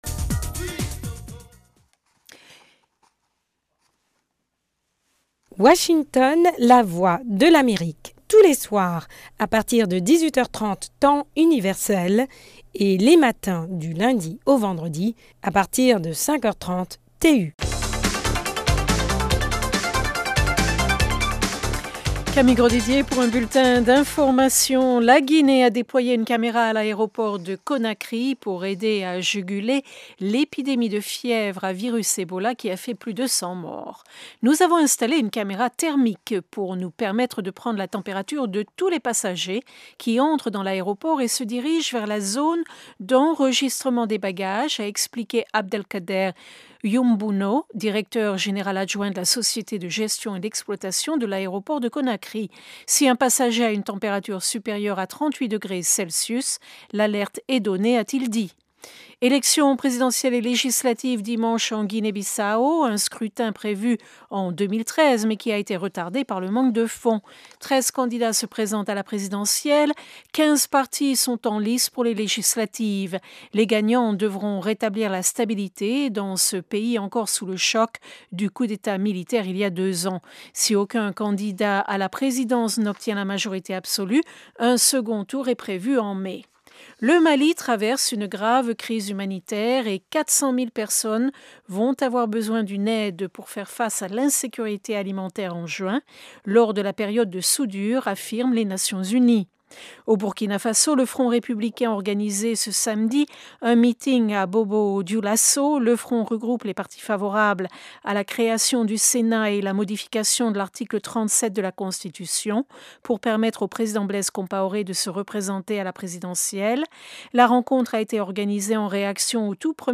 Le Magazine au Féminin de la Voix de l’Amérique propose des sujets liés à la promotion de la femme, de la jeunesse et de l’enfance. Les questions abordées, avec des invité(e)s sont d’ordre social, économique, culturel et politique.
Le Magazine au Féminin est diffusé le samedi à 19H30 Temps Universel, après le bulletin d’actualité de la journée.